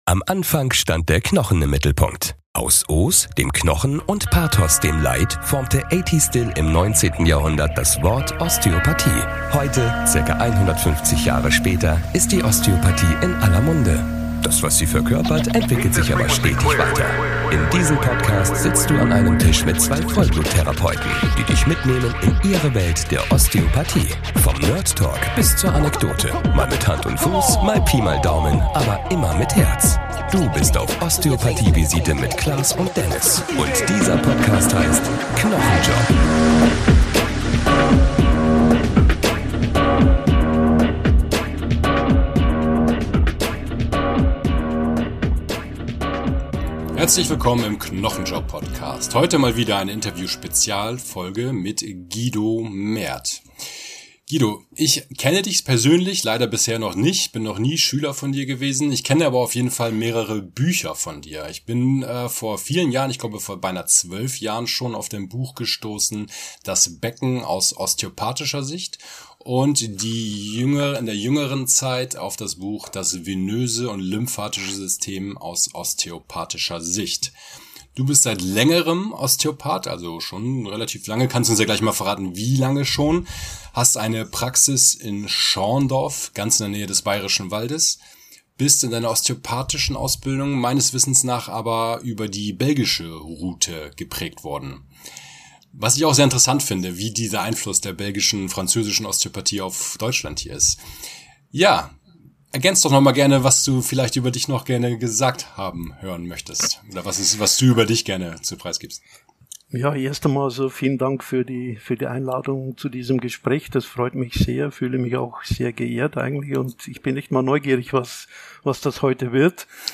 Interview-Spezial